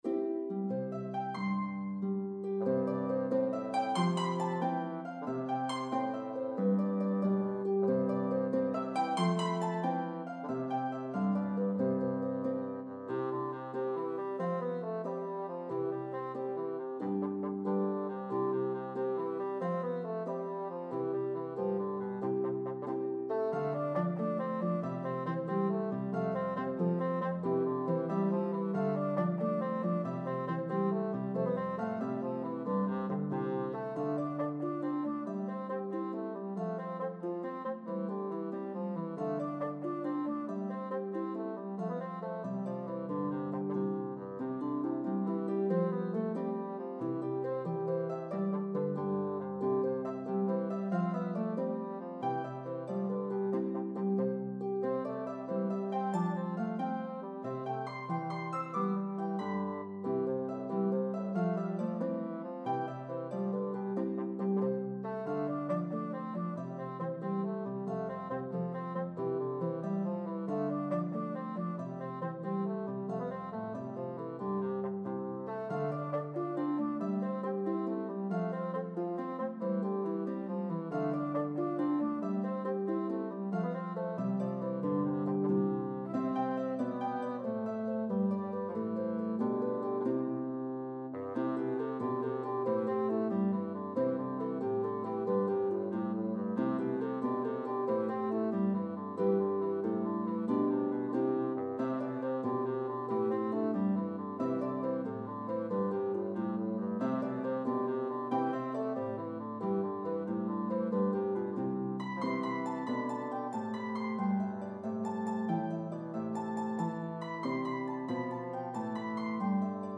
a medley of two joyful, upbeat Irish Jigs